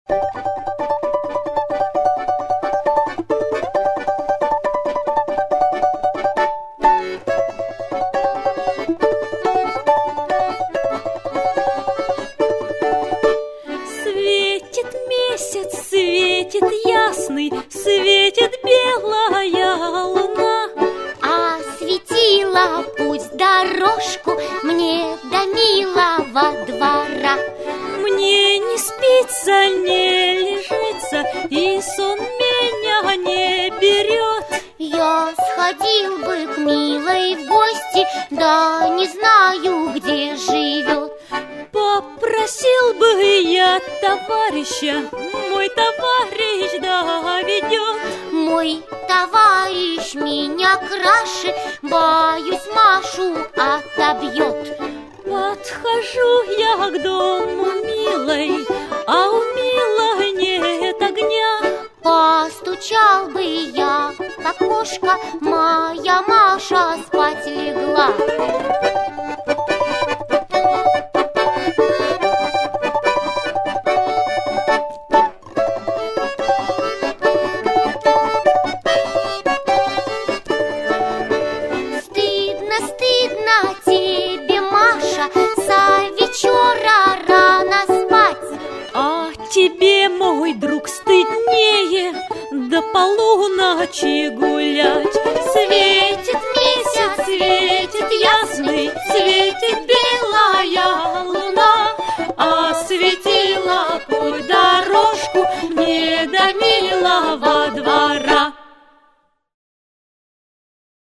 Слова и музыка народные.